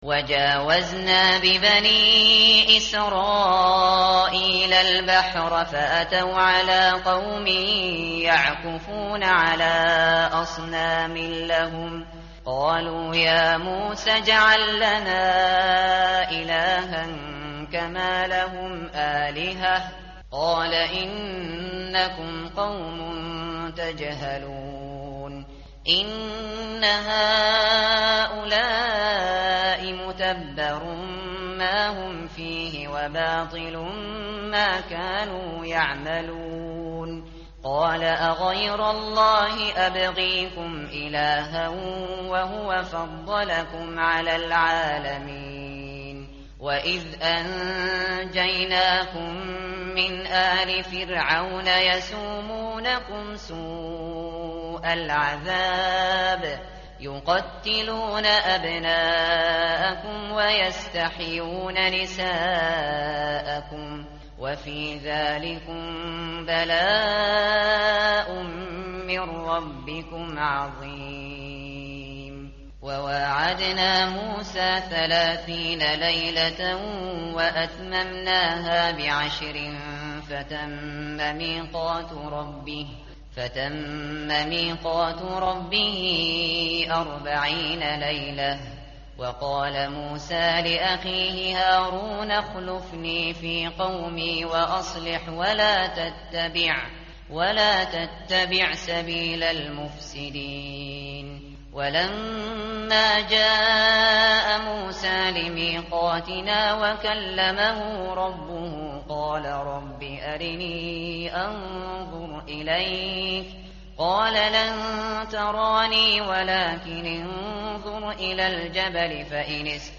متن قرآن همراه باتلاوت قرآن و ترجمه
tartil_shateri_page_167.mp3